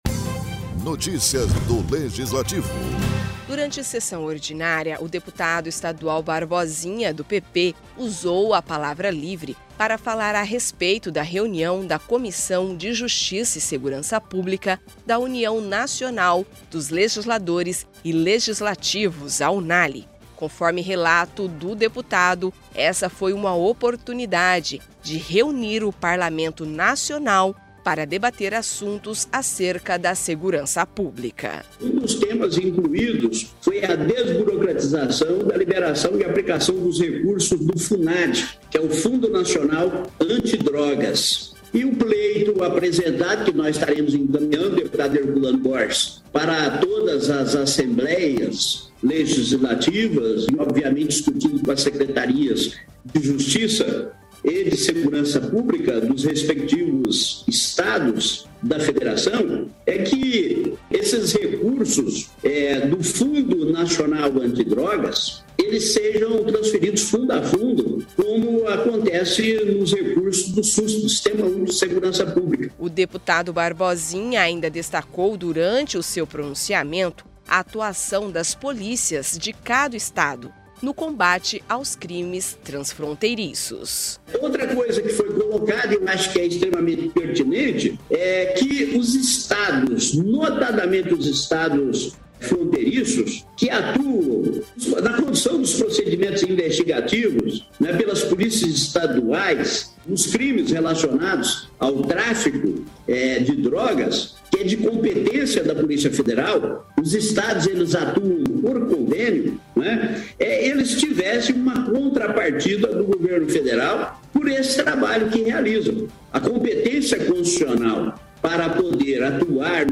Durante a sessão ordinária o deputado estadual Barbosinha (PP) usou a palavra livre para falar a respeito da reunião da Comissão de Justiça e Segurança Pública da União Nacional dos Legisladores e Legislativos (Unale).